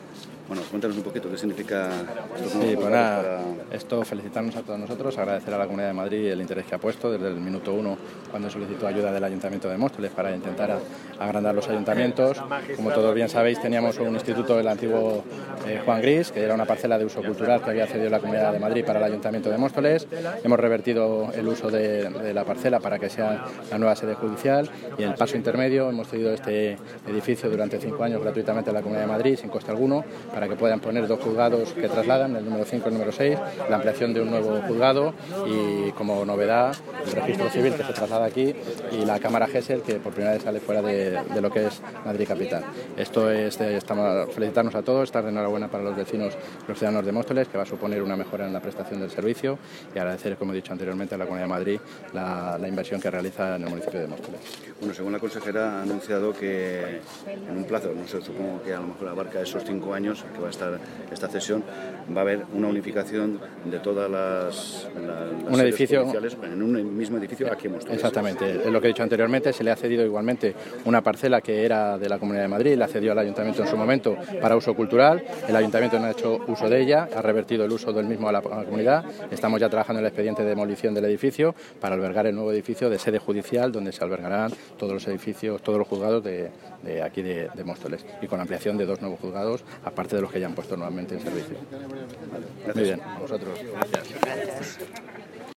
Audio - Roberto Sánchez (Concejal de Presidencia, Urbanismo, Seguridad y comunicación) Sobre visita sede judicial